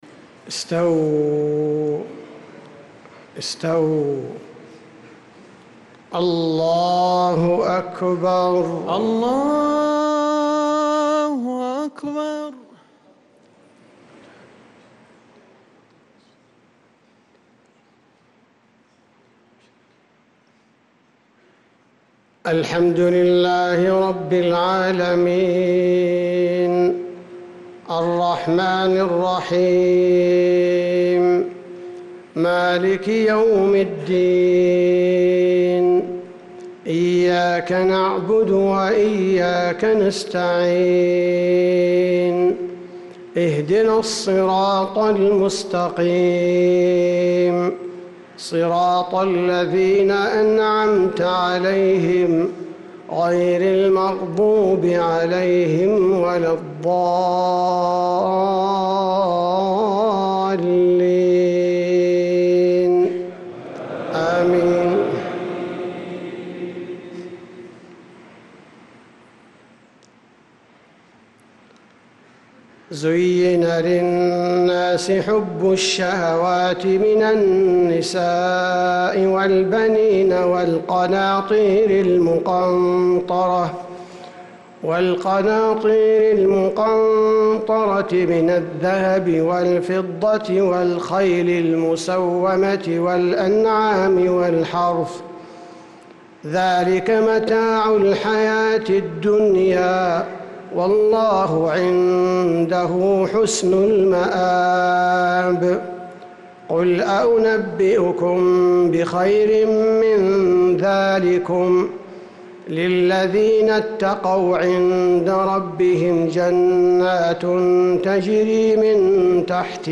صلاة المغرب للقارئ عبدالباري الثبيتي 3 ربيع الأول 1446 هـ